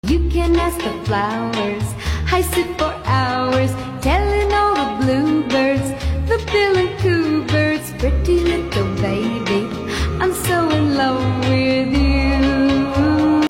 this audio is so cutesy
idk if it’s 60s or 70s